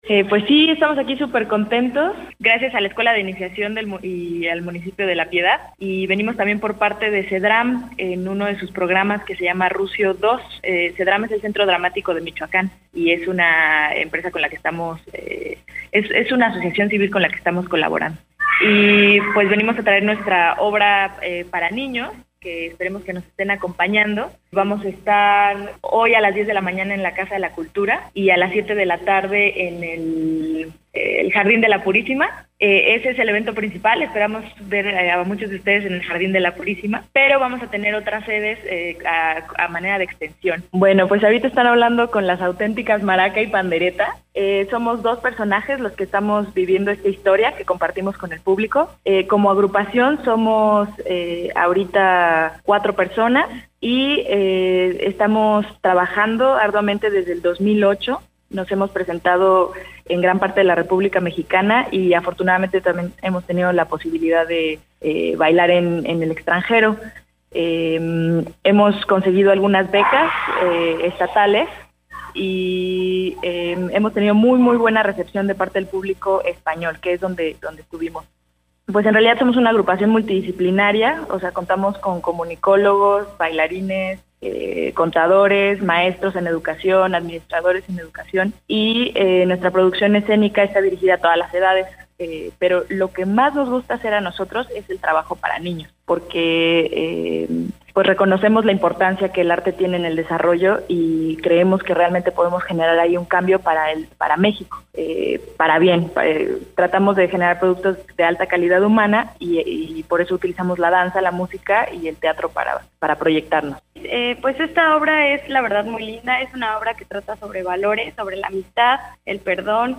tenemos vía telefónica